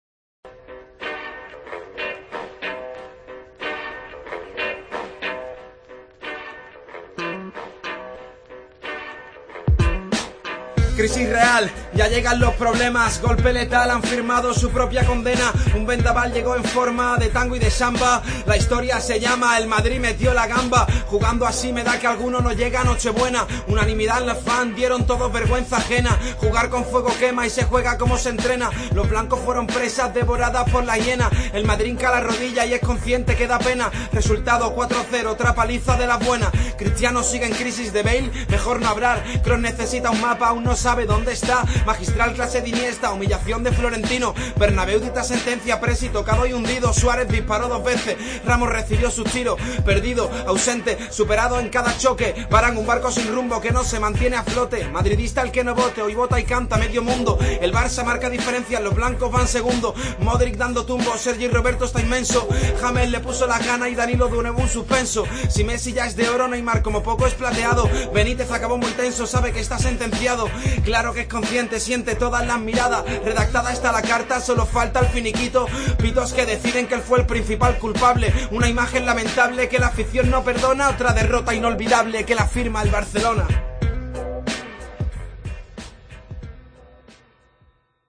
a ritmo de rap